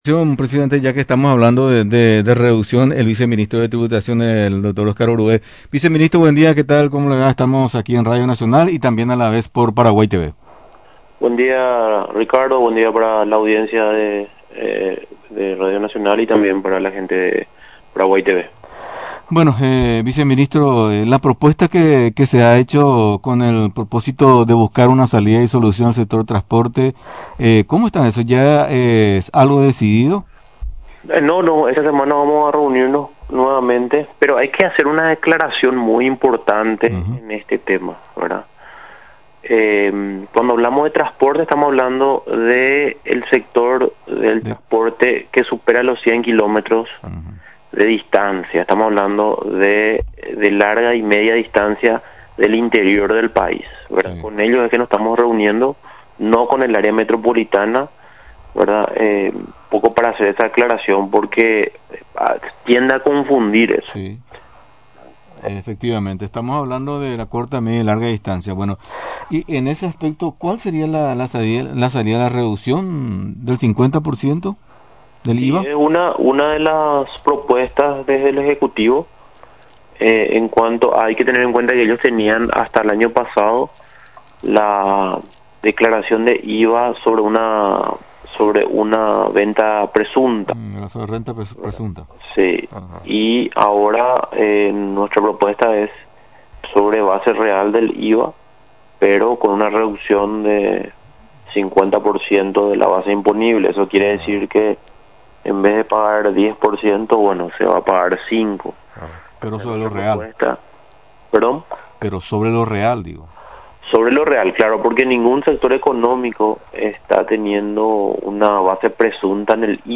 Con respecto a este último, la entidad previsora aparentemente no está de acuerdo con la petición planteada por los representantes de ese sector de transporte, de nuestro país, pero se define esta semana, lo argumentó el viceministro, Oscar Orué, entrevistado en Radio Nacional del Paraguay 920 AM.